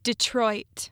(də-TROIT)